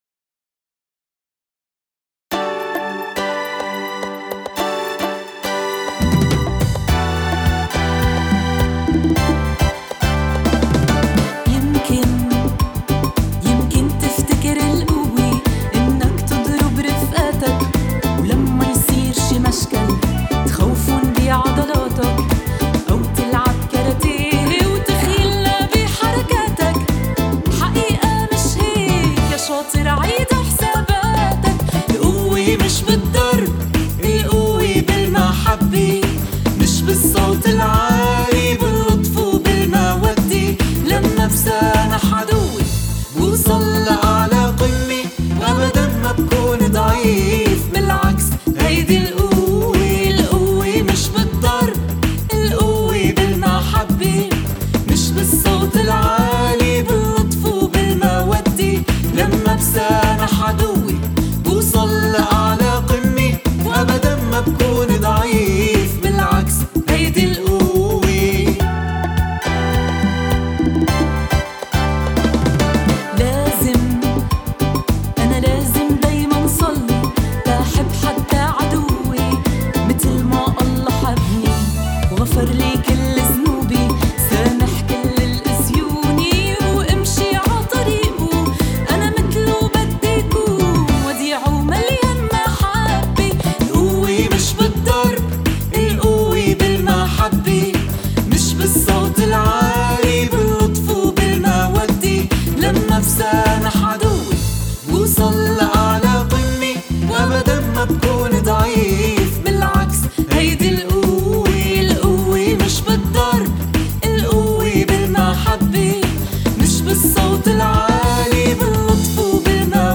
G Major
105 BPM